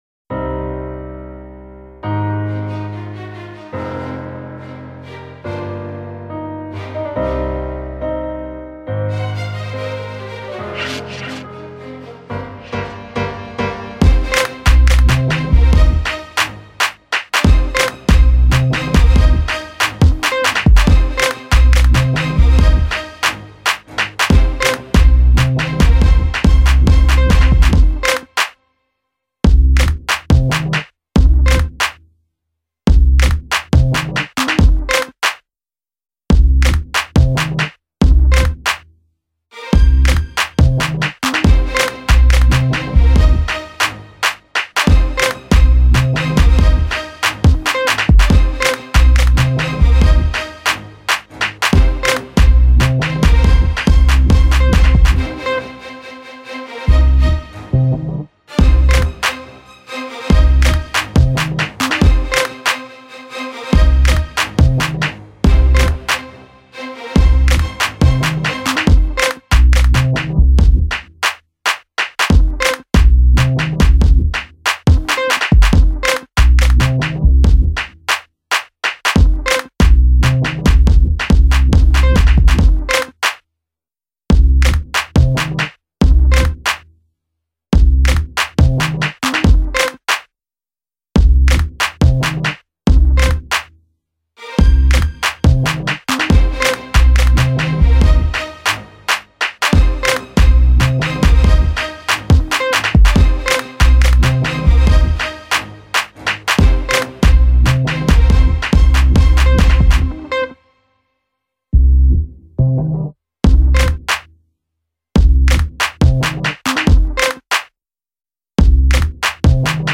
garage , house